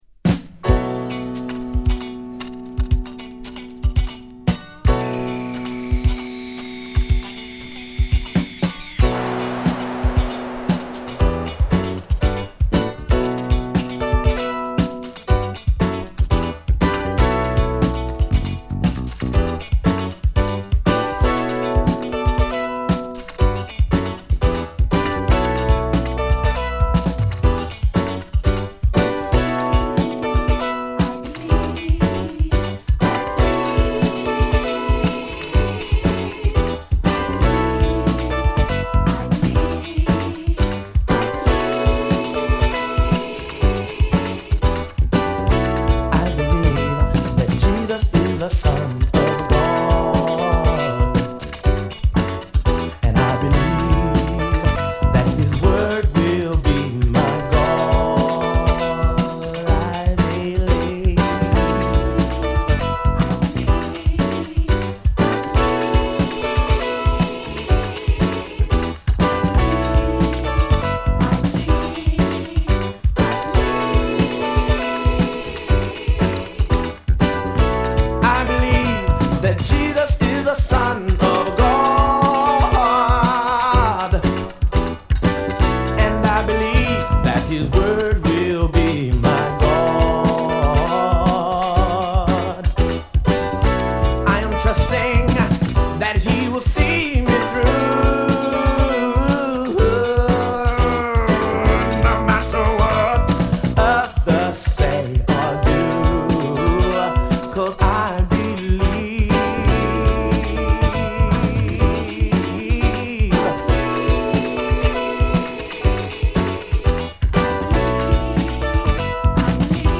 小気味よいリムショットにピアノが絡み
どの曲もゴスペル臭さは控えめでオブスキュアなソウル／ブギーとして◎！！
＊試聴はA→B1→B2です。